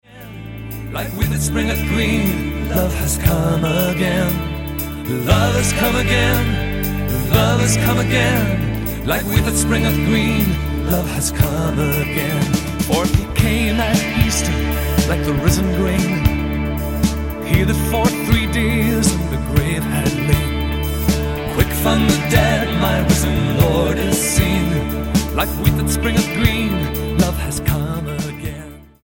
STYLE: Pop
acoustic guitar